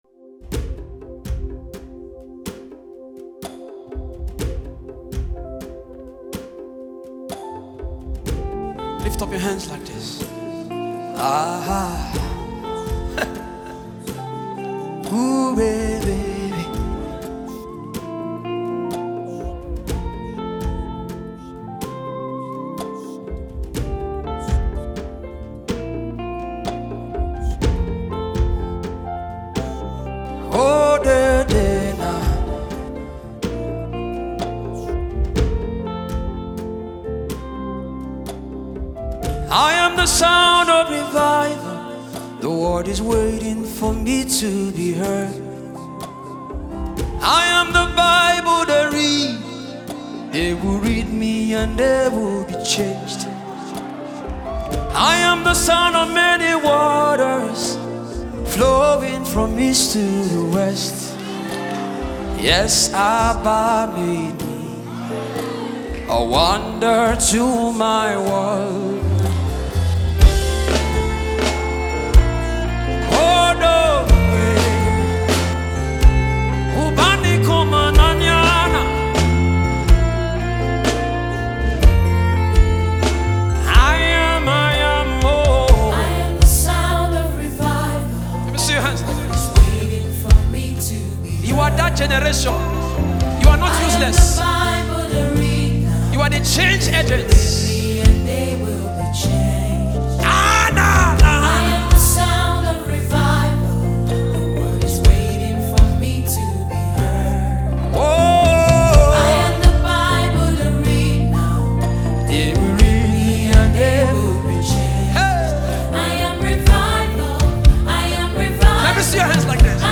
gospel record